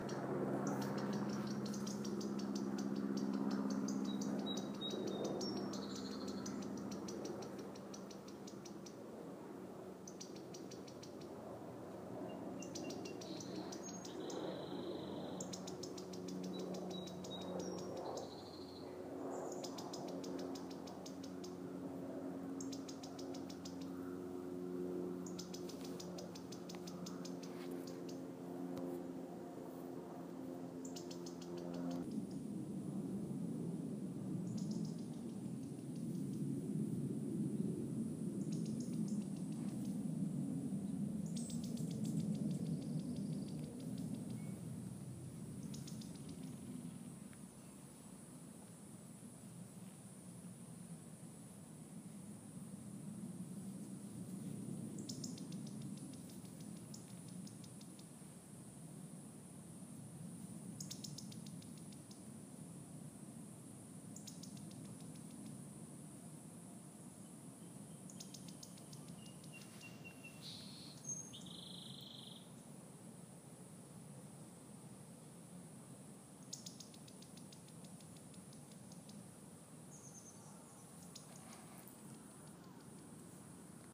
CAQU – murmuring in thick vines, 3-4 individuals total